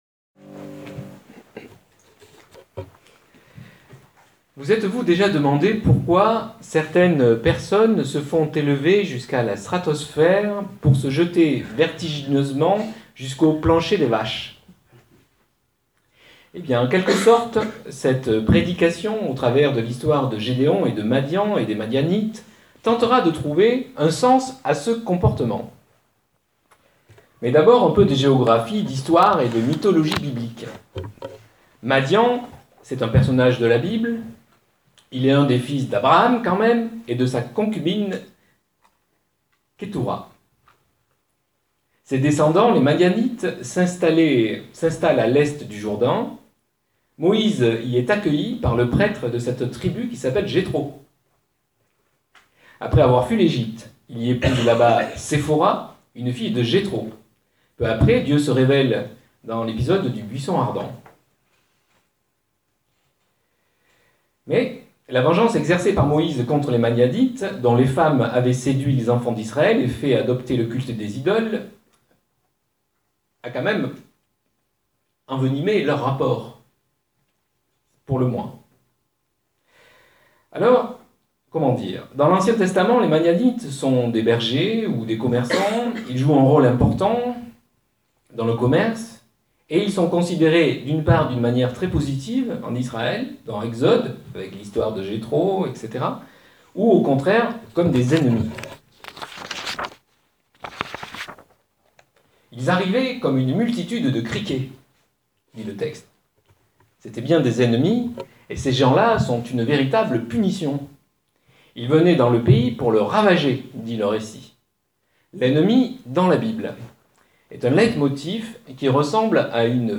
PREDICATION AUDIO DU CULTE DU 2 NOVEMBRE 2014, Maison Fraternelle